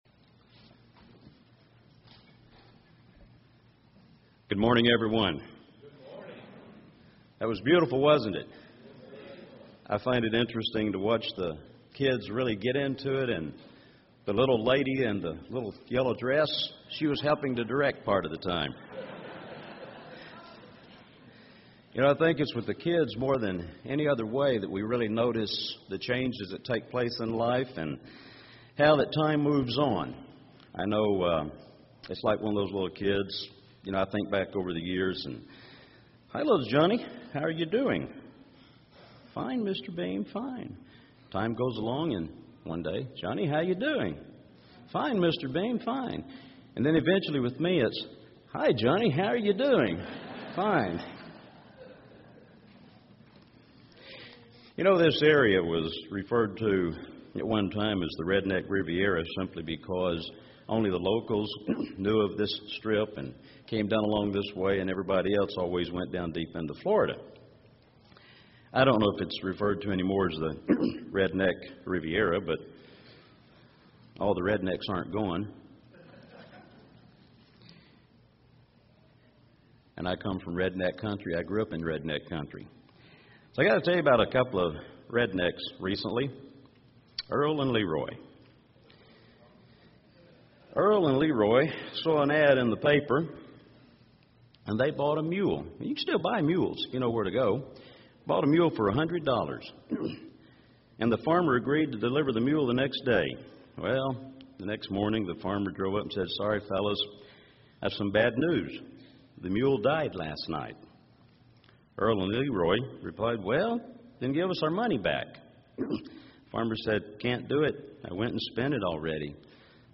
This sermon was given at the Panama City Beach, Florida 2011 Feast site.